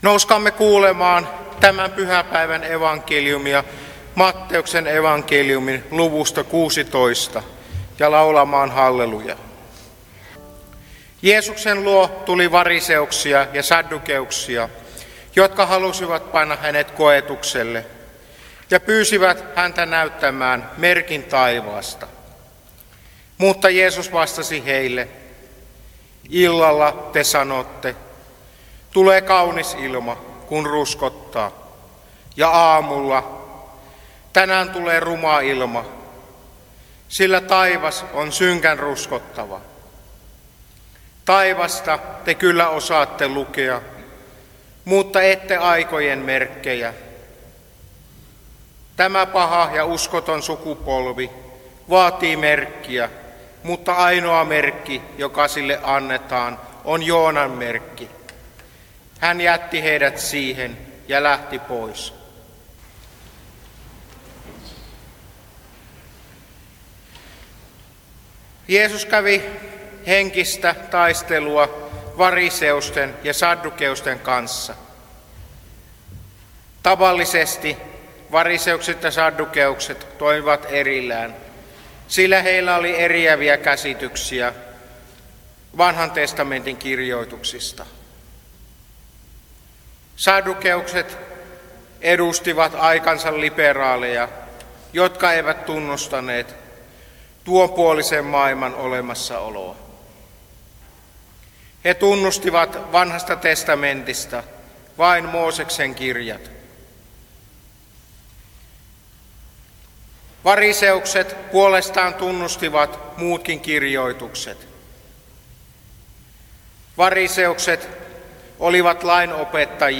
sanajumalanpalveluksessa Teuvalla uuden vuoden aattona